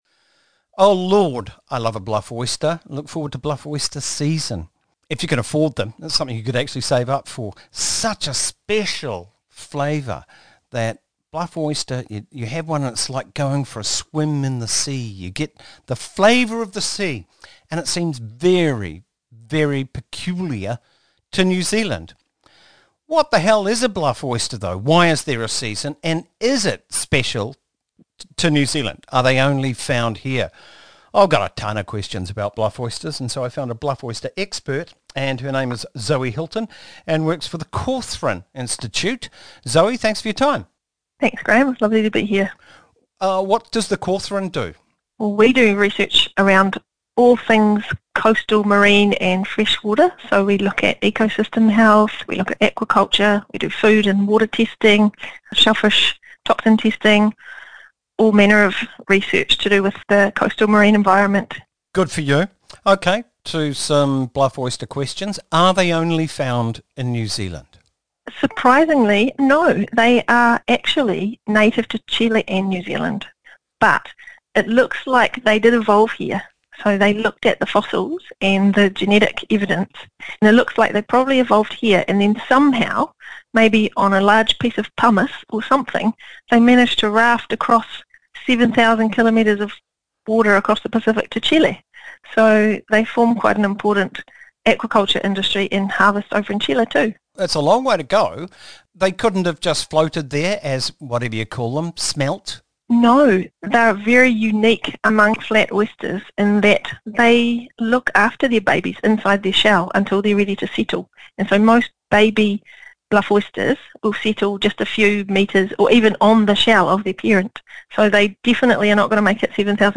The iconic Bluff Oyster - An interview